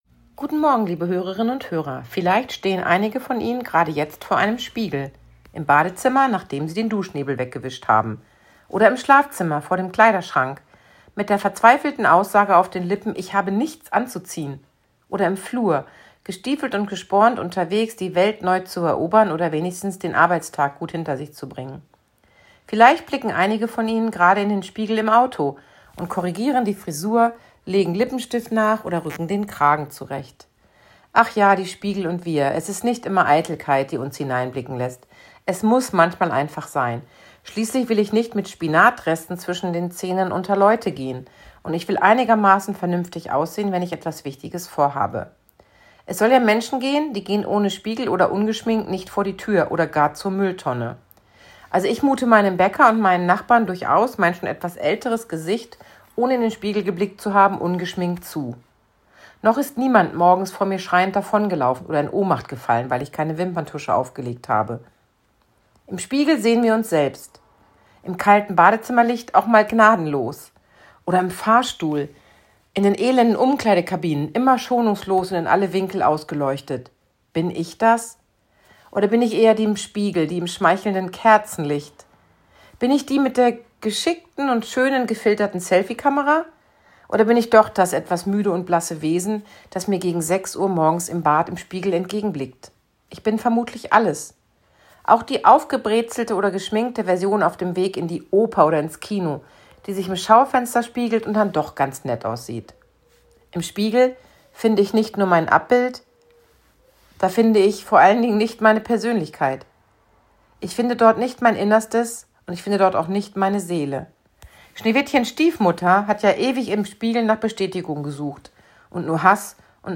Radioandacht vom 3. September